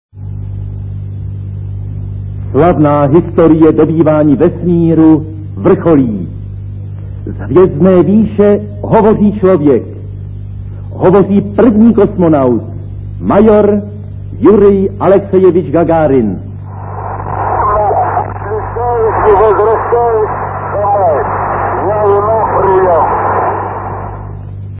Il faut garderer à l'esprit que la plupart des enregistrements sont anciens.